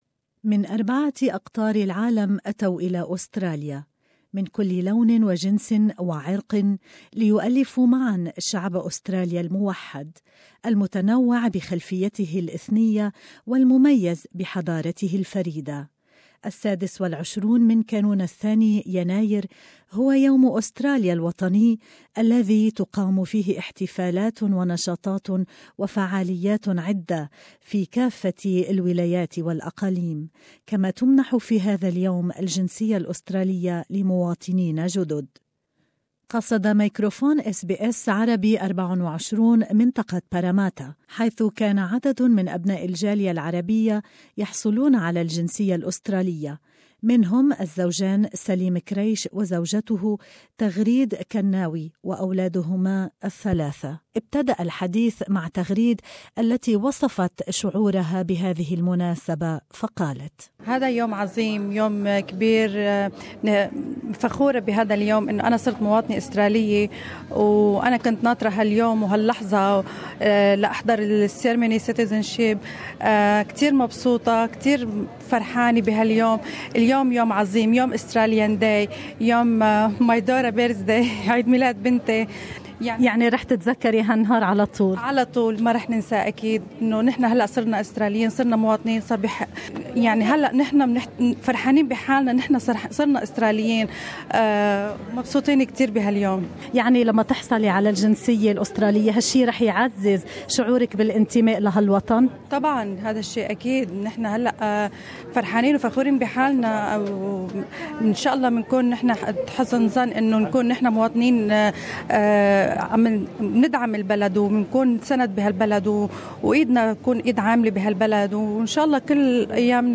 SBS Arabic 24 coverage of Australia day at Parramatta.